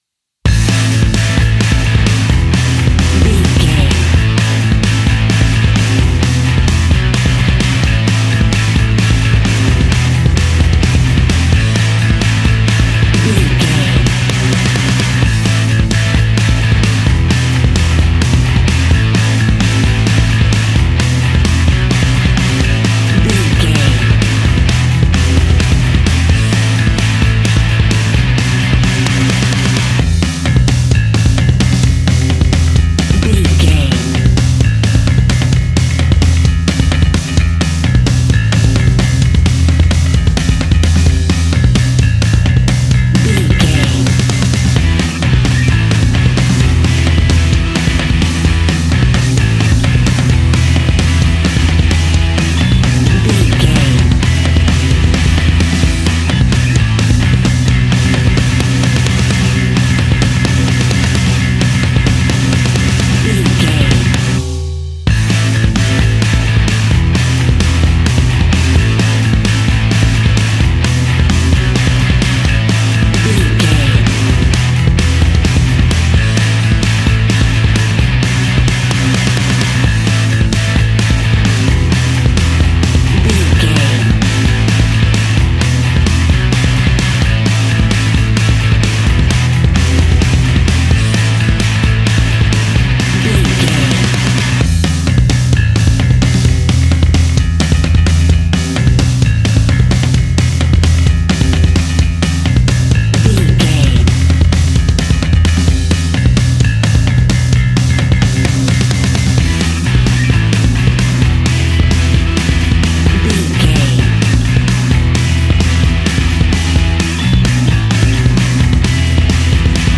Ionian/Major
energetic
driving
heavy
aggressive
electric guitar
bass guitar
drums
hard rock
distortion
punk metal
rock instrumentals
distorted guitars
hammond organ